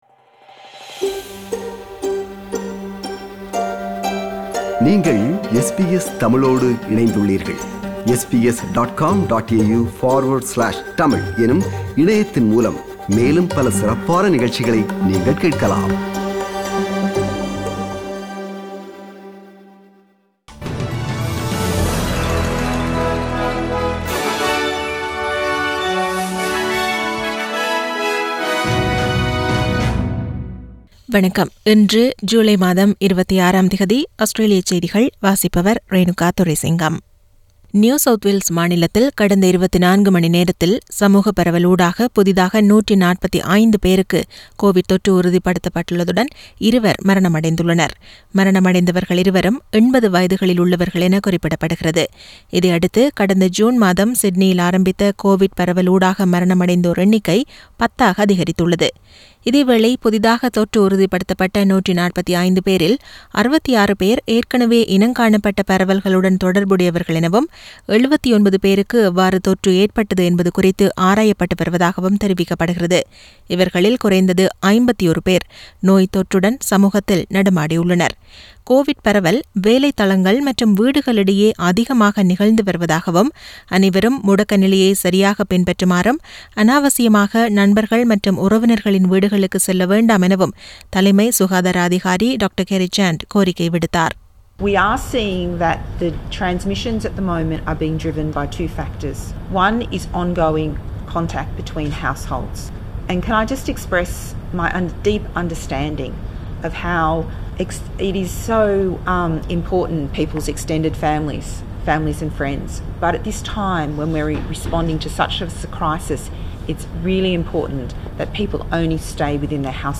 SBS தமிழ் ஒலிபரப்பின் இன்றைய (திங்கட்கிழமை 26/07/2021) ஆஸ்திரேலியா குறித்த செய்திகள்.